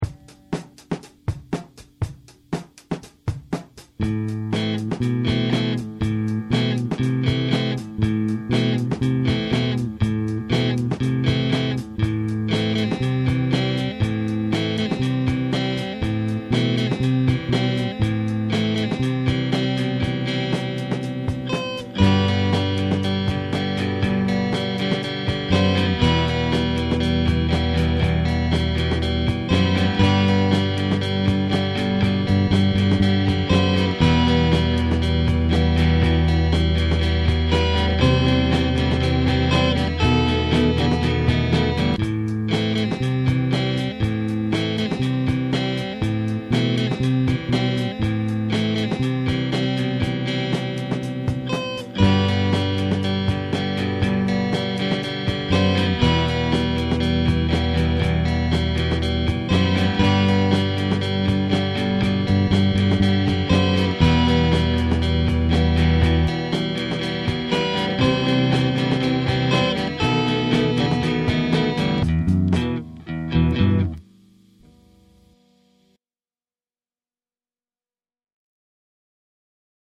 Here is the non-tweaked version of a song I’m working on. No lyrics recorded yet. Temporary drums.